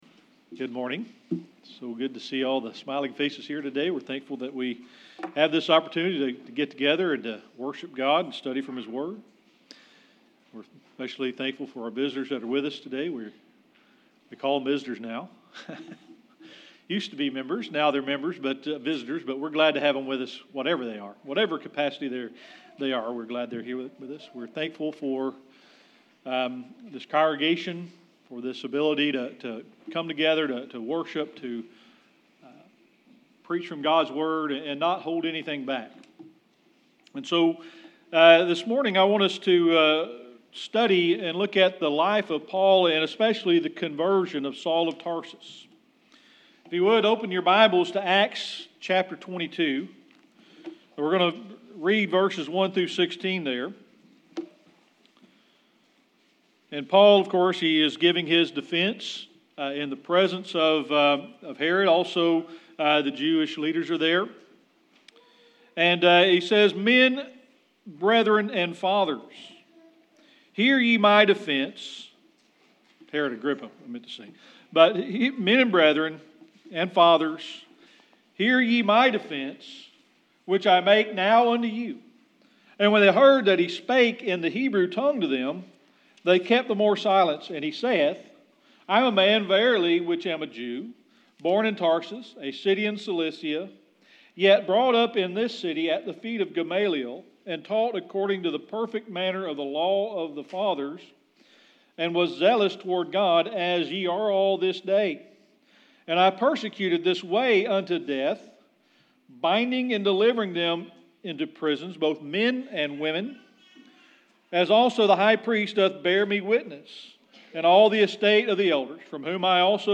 Sermon Archives
Service Type: Sunday Morning Worship